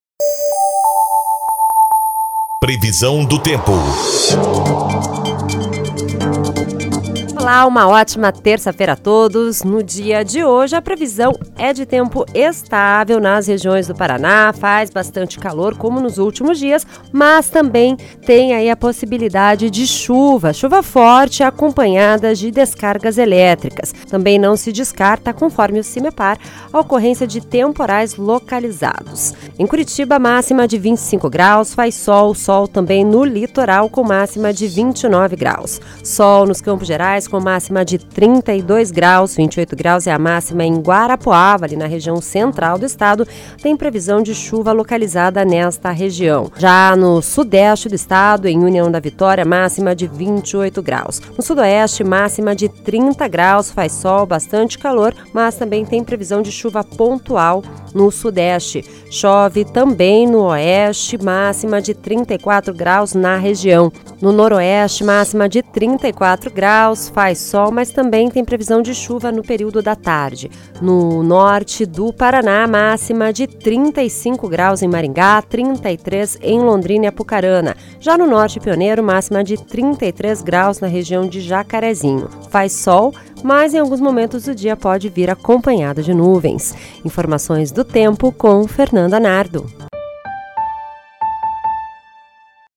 Previsão do Tempo (19/12)